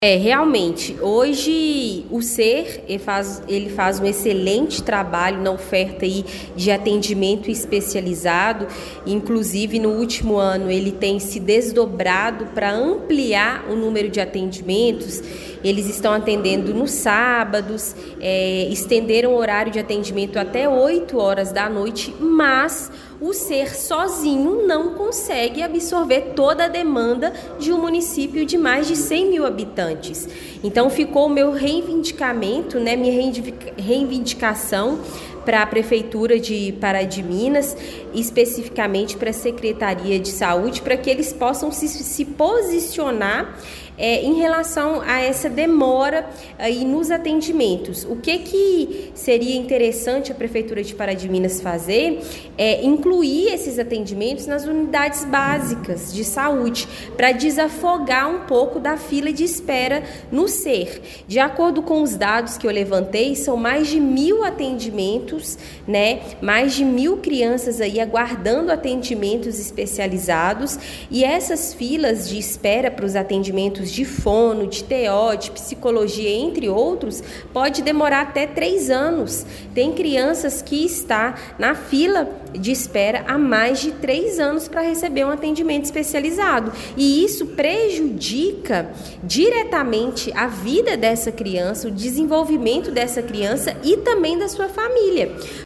Durante a reunião da Câmara Municipal, realizada ontem, 2 de dezembro, a vereadora Camila Gonçalves de Araújo fez um pronunciamento contundente sobre a longa espera enfrentada por crianças que necessitam de atendimento com fonoaudiólogos e terapeutas ocupacionais.